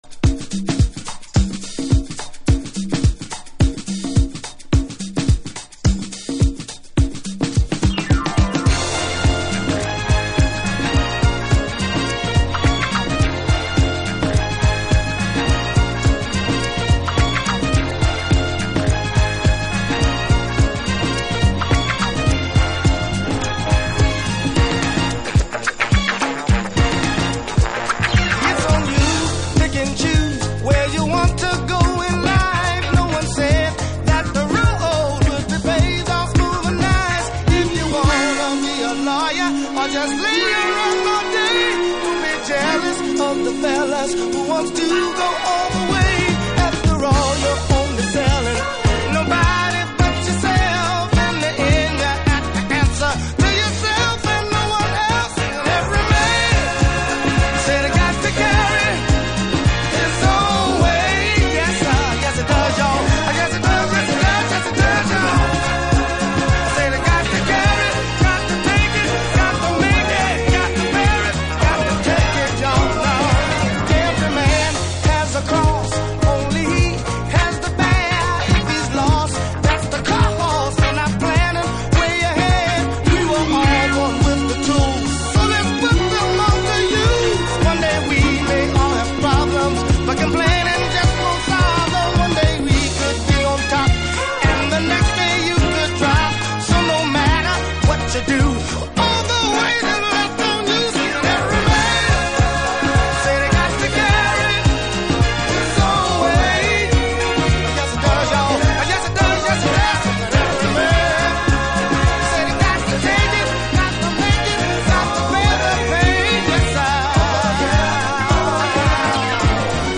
TOP > Alt Disco / Boogie > VARIOUS